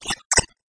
Upbeat, latin-infused club banger.